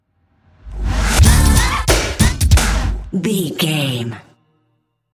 Aeolian/Minor
drum machine
synthesiser
Eurodance